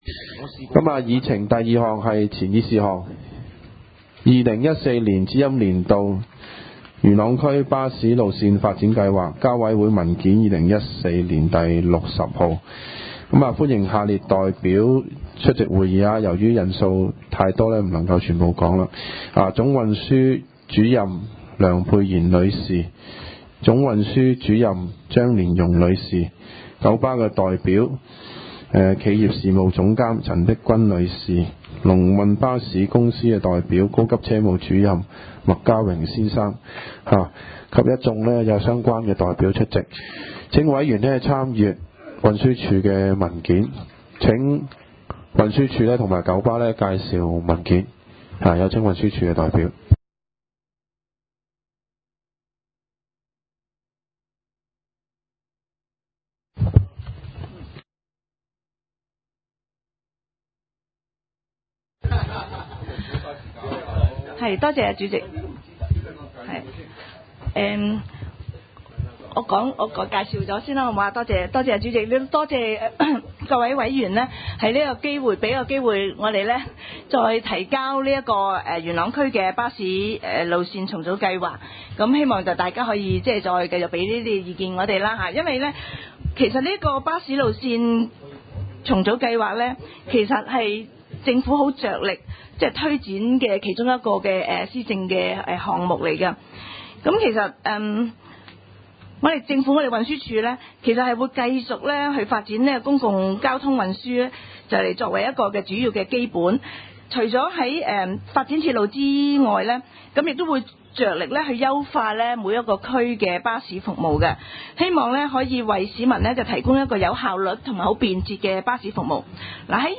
委员会会议的录音记录
地点: 元朗桥乐坊2号元朗政府合署十三楼会议厅